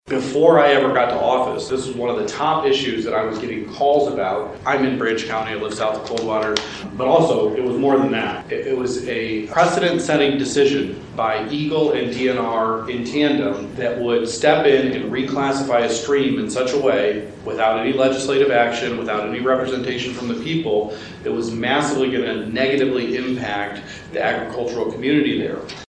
The Sturgis Area Chamber of Commerce and the Three Rivers Area Chamber of Commerce hosted a Legislative Breakfast on April 11.